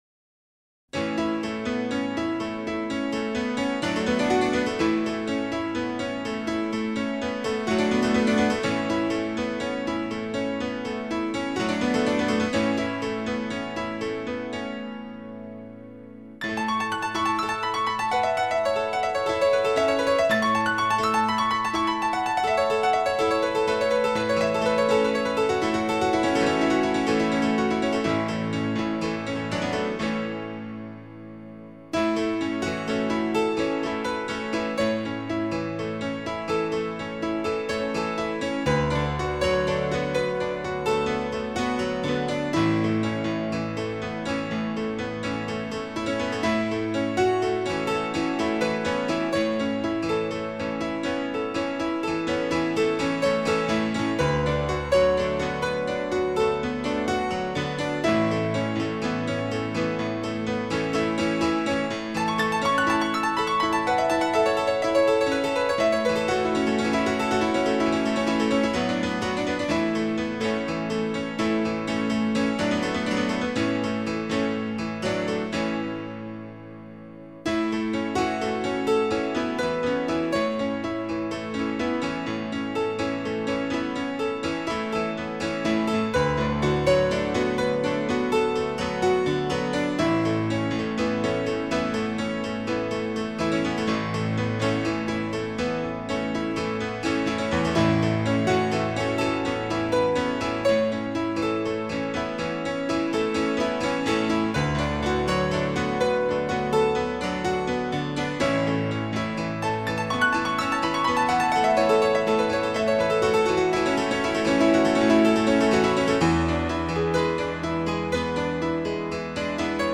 僅低音質壓縮 , 供此線上試聽